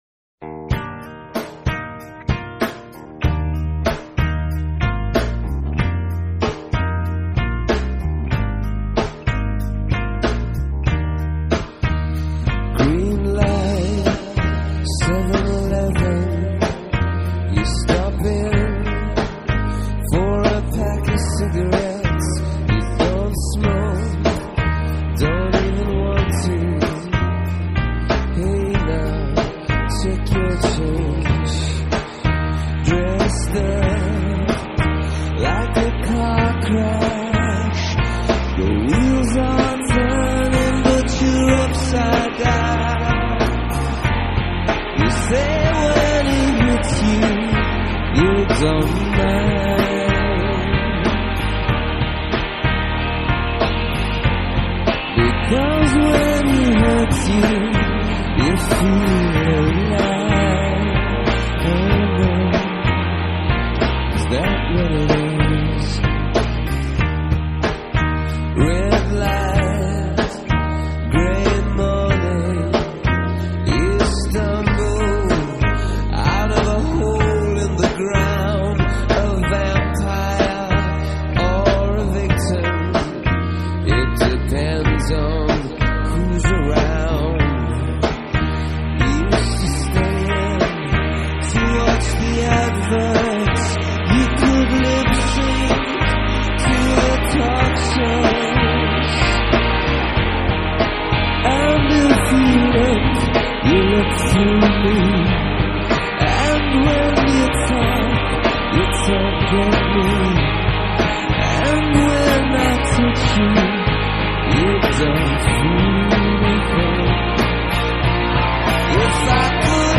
Rock, Pop, Electronic